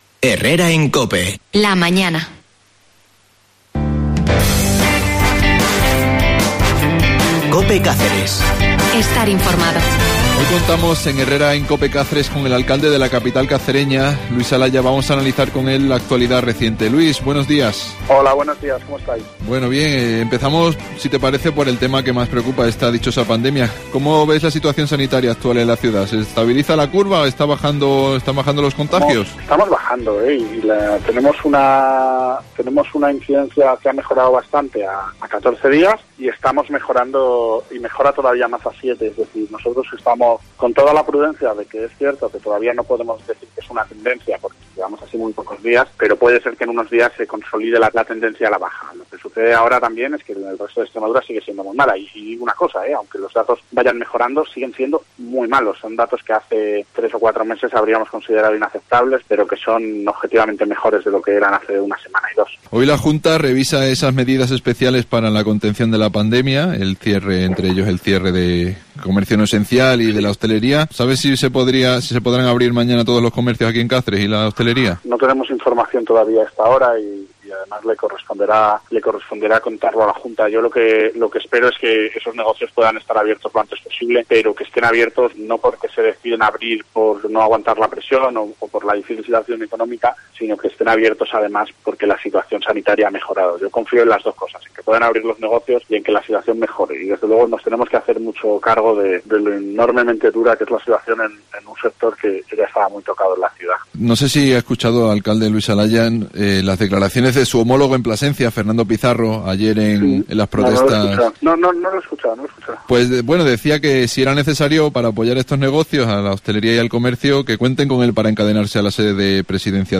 Hoy entrevistamos al alcalde de Cáceres, Luis Salaya.